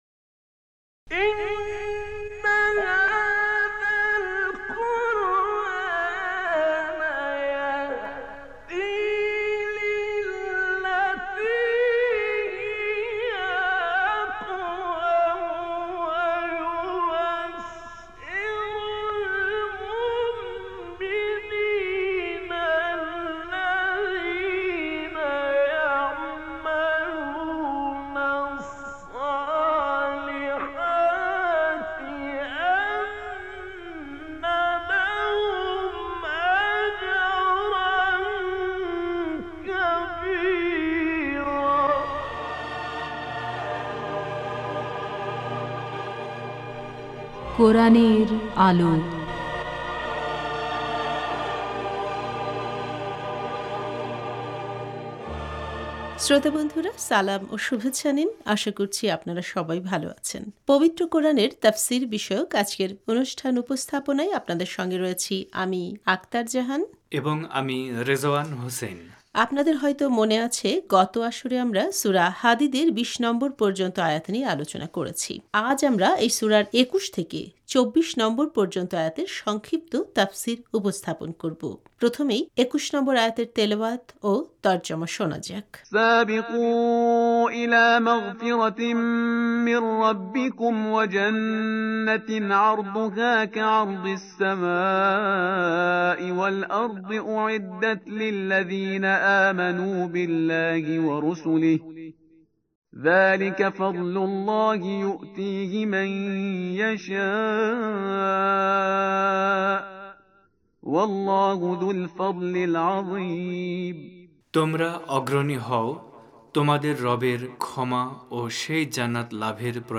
আজ আমরা এই সূরার ২১ থেকে ২৪ নম্বর পর্যন্ত আয়াতের সংক্ষিপ্ত তাফসির উপস্থাপন করব। প্রথমেই ২১ নম্বর আয়াতের তেলাওয়াত ও তর্জমা শোনা যাক: